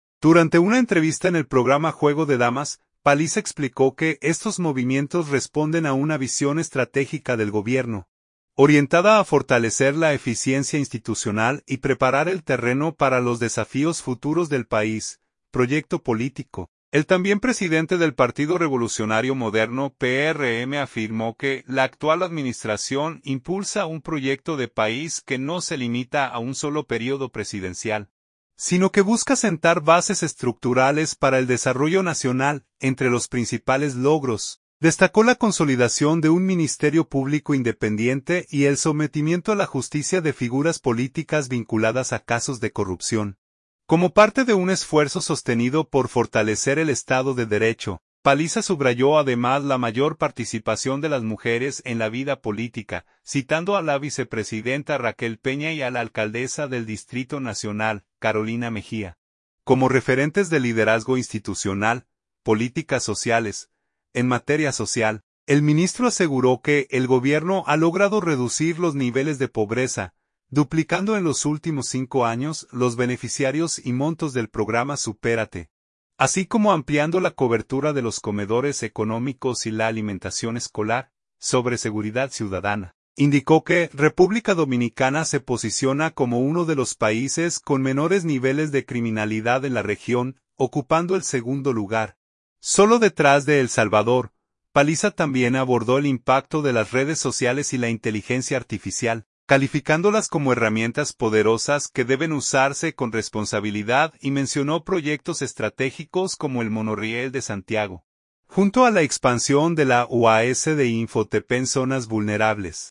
Durante una entrevista en el programa Juego de Damas, Paliza explicó que estos movimientos responden a una visión estratégica del Gobierno, orientada a fortalecer la eficiencia institucional y preparar el terreno para los desafíos futuros del país.